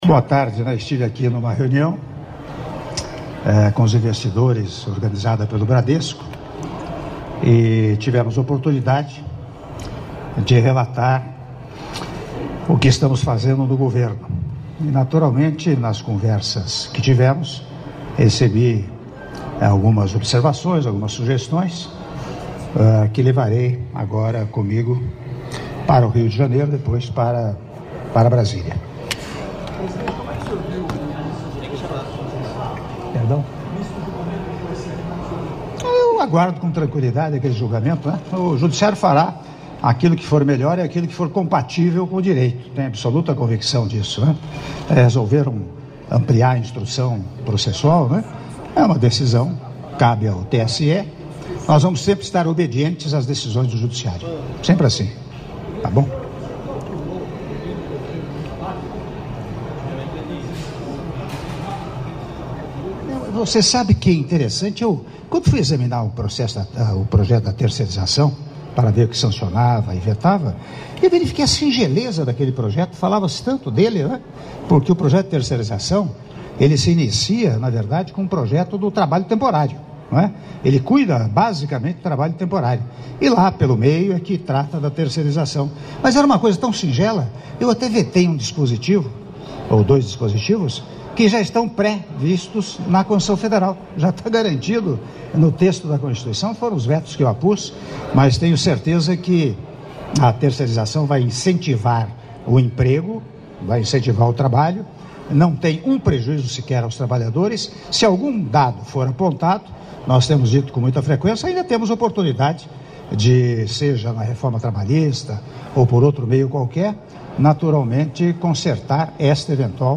Áudio da entrevista coletiva concedida pelo Presidente da República, Michel Temer, após a 4ª edição do Brazil Investment Forum - São Paulo/SP - (03min53s) — Biblioteca